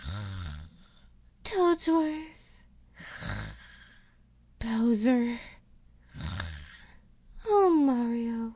peach_snoring3.ogg